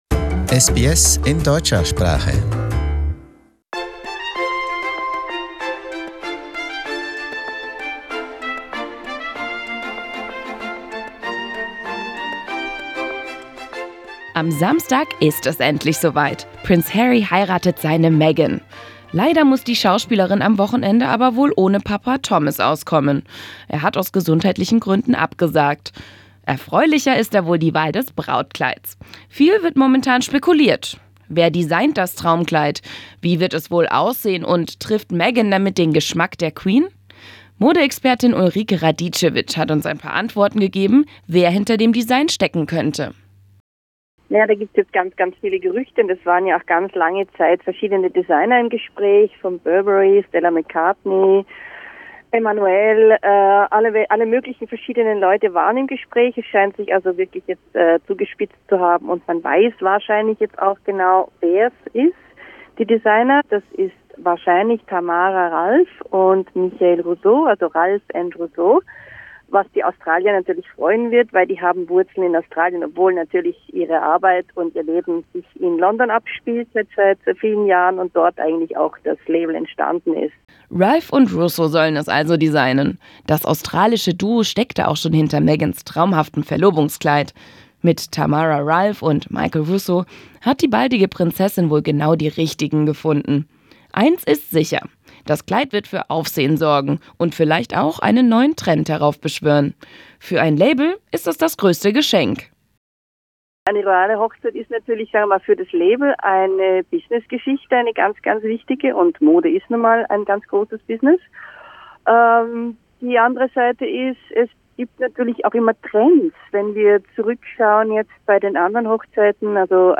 The designers of the wedding dress – an Australian duo. We spoke to an Australian based Austrian fashion expert.